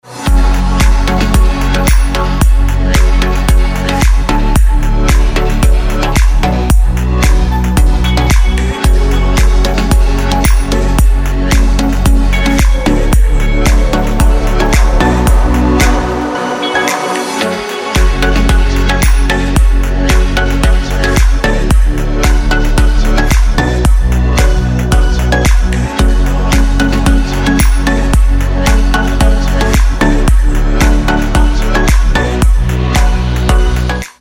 • Качество: 320, Stereo
ритмичные
deep house
dance
без слов
house